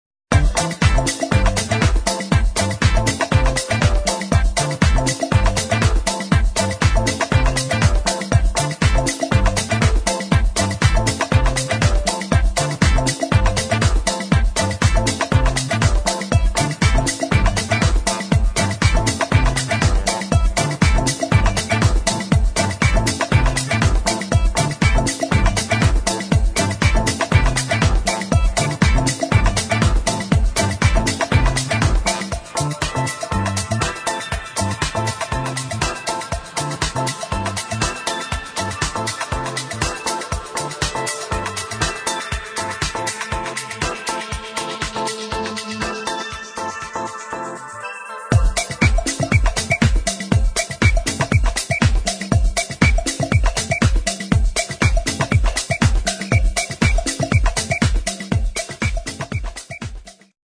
[ AFRO FUNK | HOUSE | BREAKBEAT ]